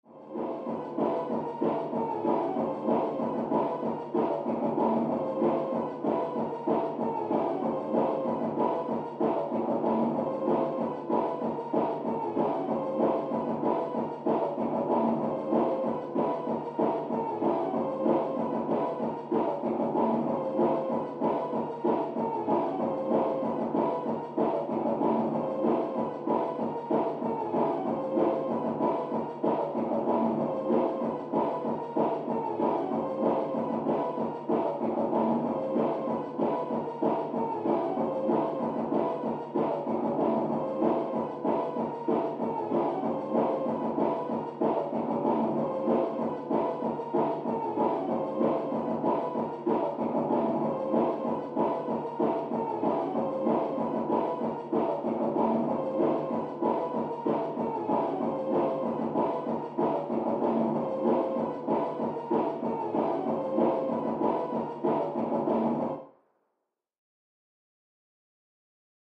Music; Electronic Dance Beat, From Next Room.